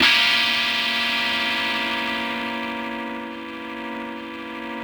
ChordGdim7.wav